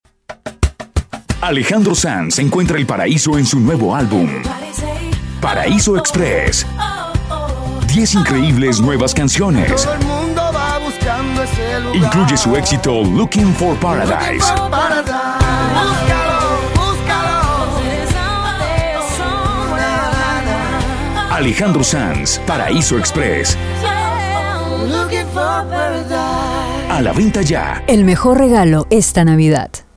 spanisch Südamerika
Sprechprobe: Werbung (Muttersprache):
spanish male voice over artist